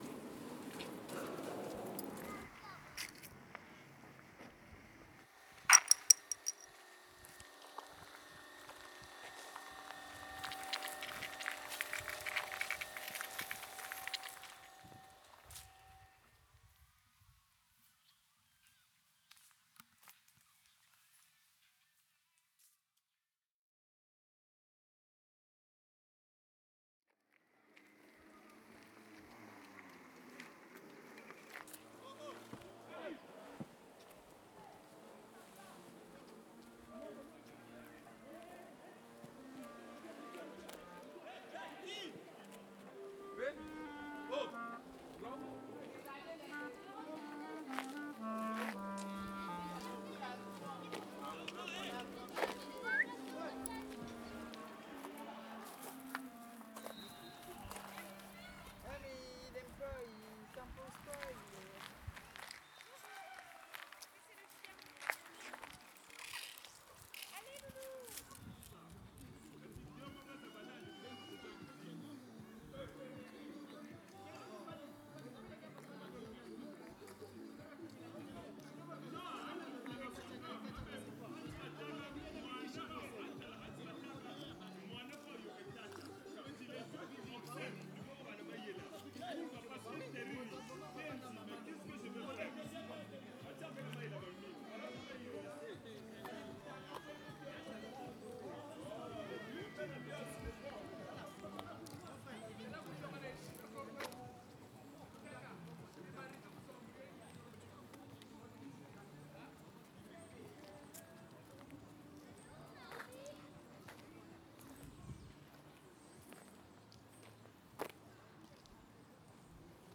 MEL / Balades sonores / SoundSail
Le but est de redécouvrir des sons du quotidien ou (et) d’en découvrir de nouveaux dans un contexte d’écoute partagée.
Les balades sonores peuvent optionnellement être enregistrées, puis retravaillées en compositions
Lorient centre